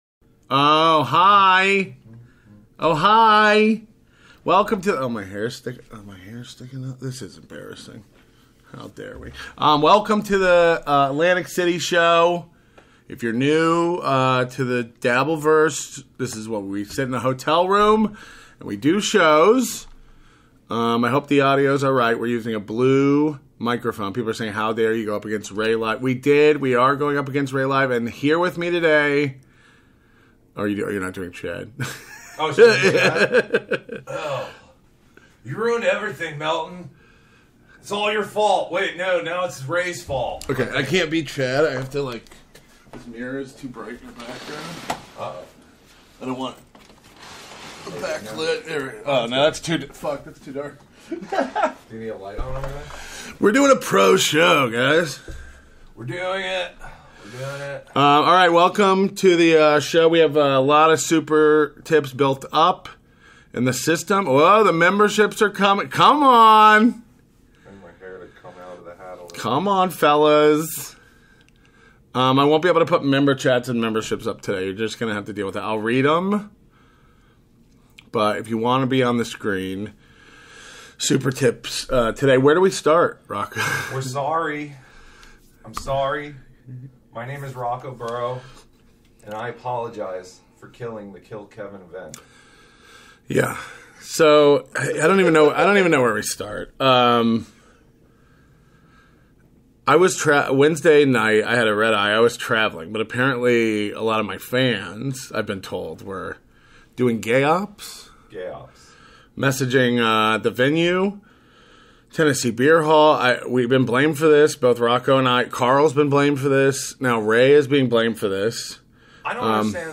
February 7, 2025: LIVE In Atlantic City!